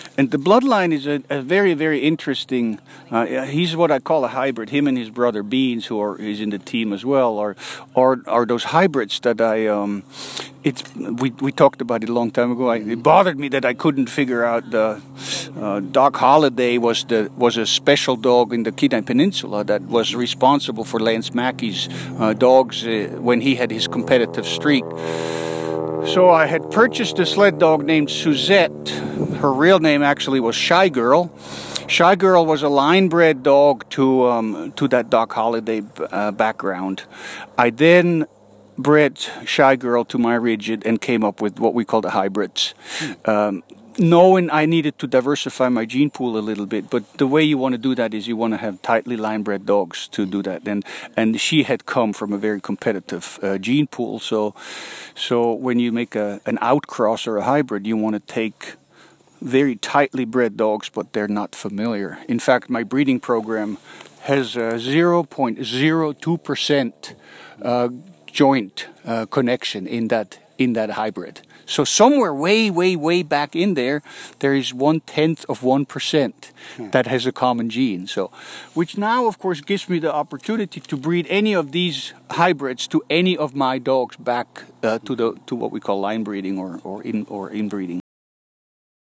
Current Location: WHITE MOUNTAIN, ALASKA
Temperature: 24F / OUTDOORS